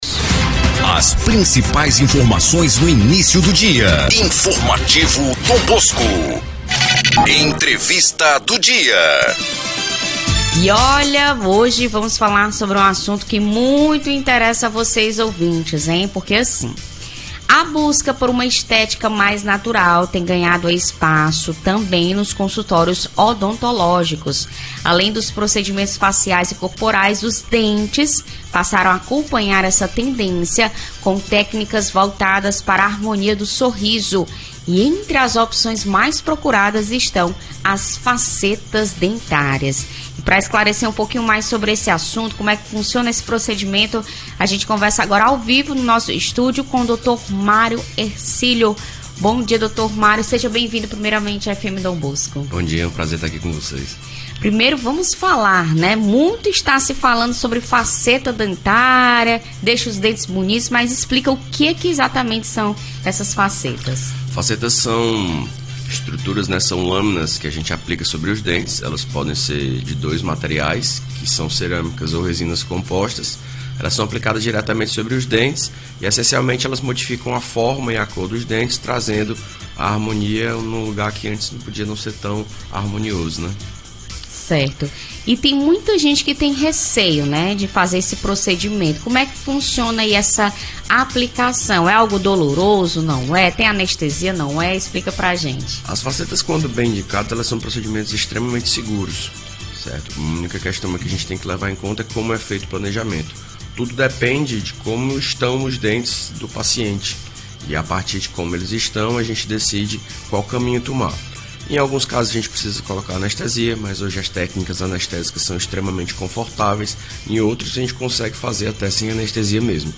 ENTREVISTA-2212.mp3